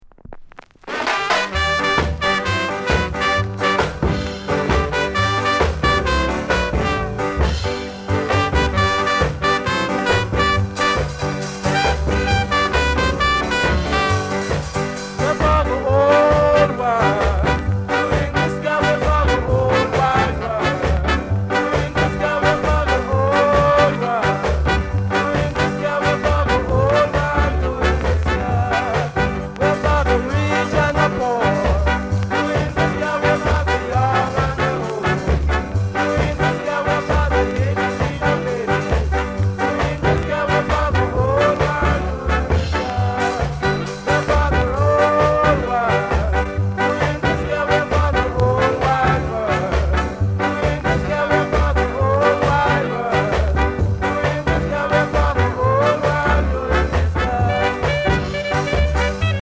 NICE INST